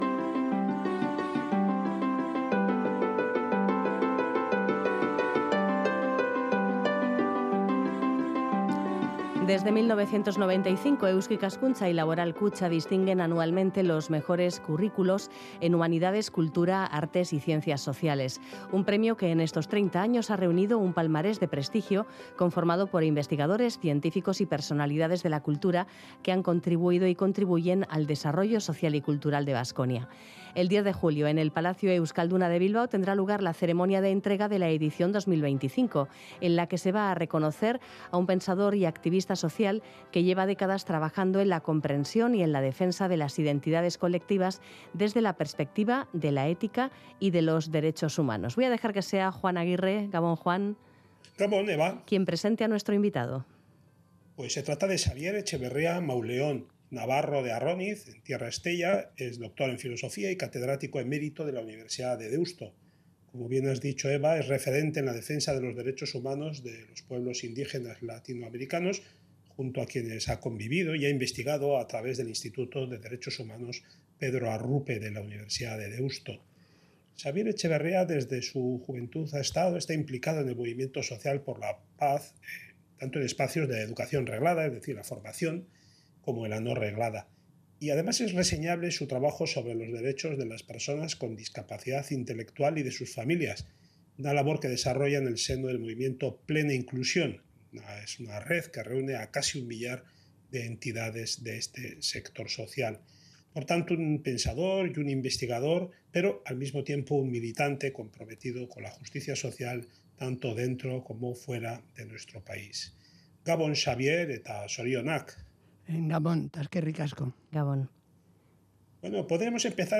En vísperas de la ceremonia de entrega del galardón, dialogamos con él sobre los fundamentos de la ética y su actualidad aquí y ahora, su valor ante el desarrollo tecnológico y la relación entre comunidad y ética.